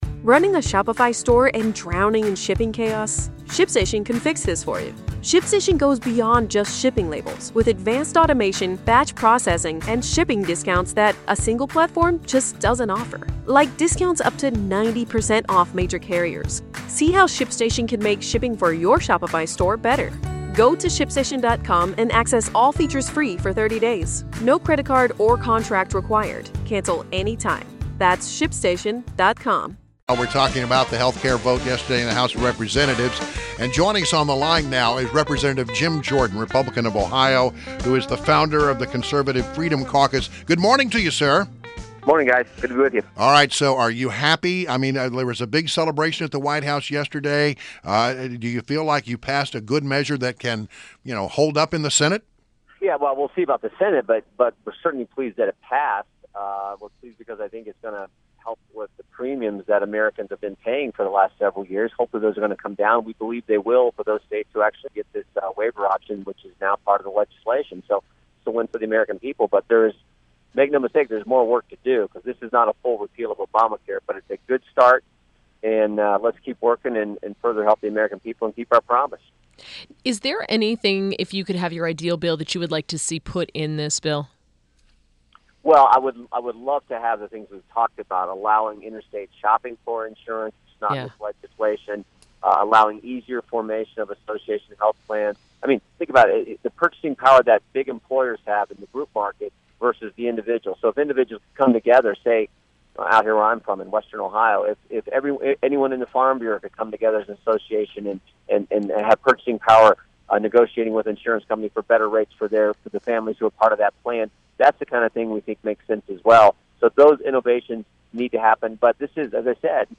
WMAL Interview - REP. JIM JORDAN - 05.05.17